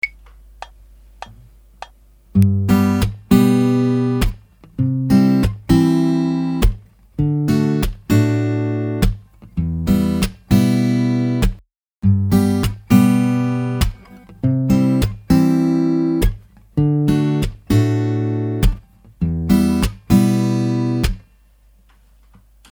The percussive sound is made by the strings hitting the frets on the guitar.
Try the example below that use the same 4 chords and the moving thumb as in the previous example but with the added complication of the dead hits on beats 2 and 4.
Thumb Move With Dead Hits | Download